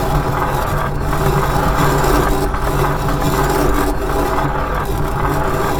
scratch.wav